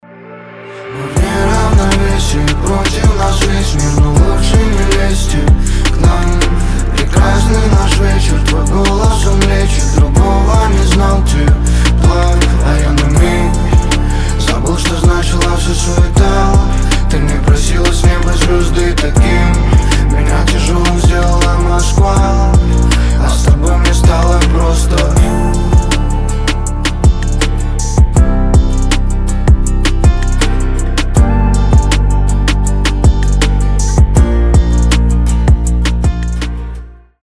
• Качество: 320, Stereo
мужской голос
лирика
мелодичные